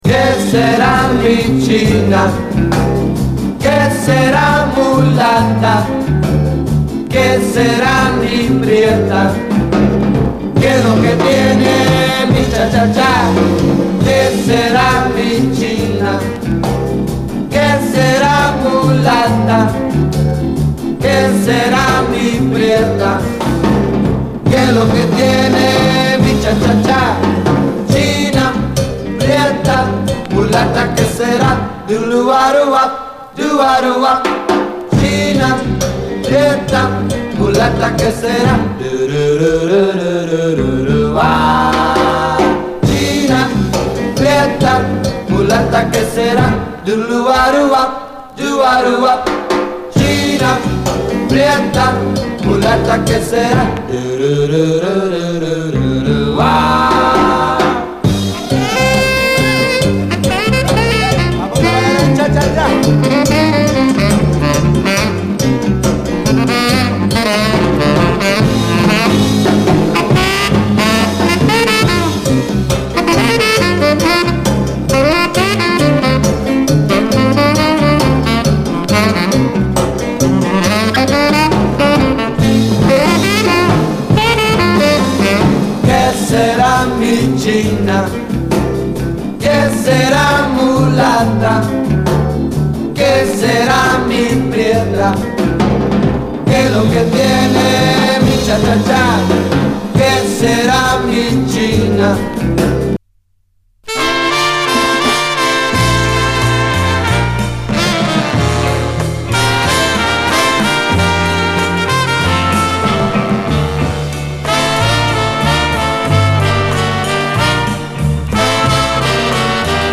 北欧アイスランド産のレア・ブルーアイド・ソウル〜S.S.W.盤！
メロウ・ボッサ